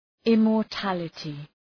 {,ımɔ:r’tælətı}